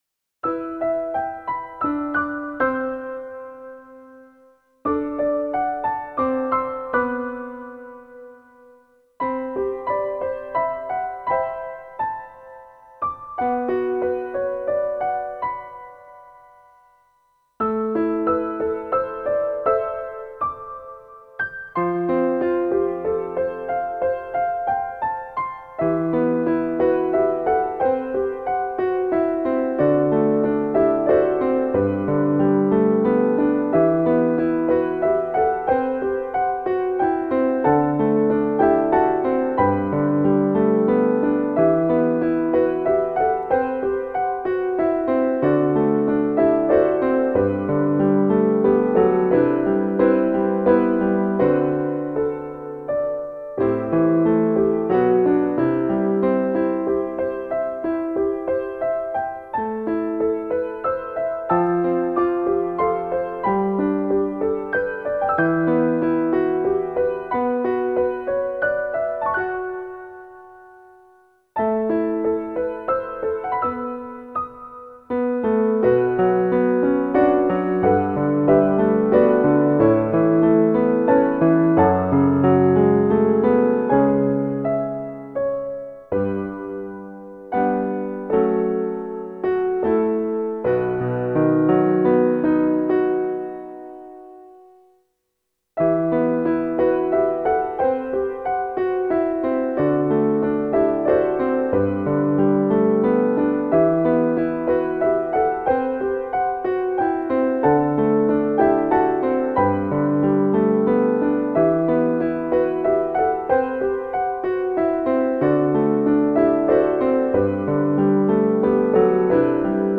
the piano piece